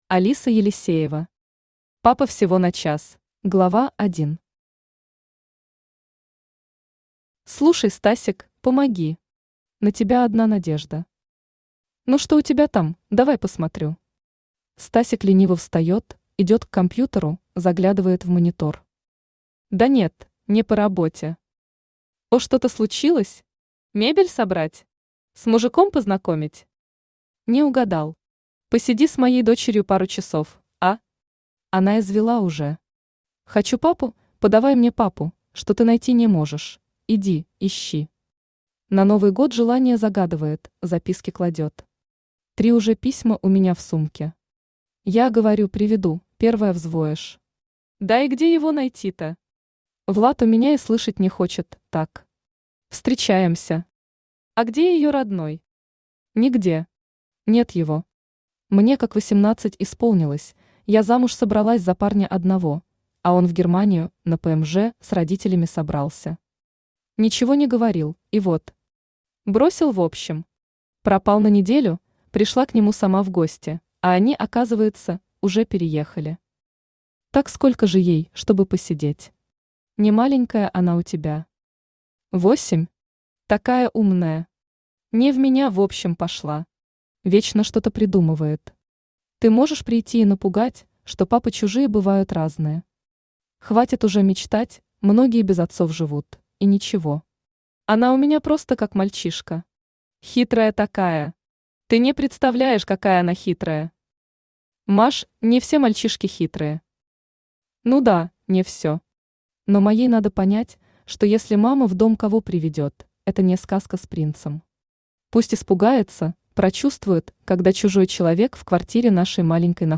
Aудиокнига Папа всего на час Автор Алиса Елисеева Читает аудиокнигу Авточтец ЛитРес.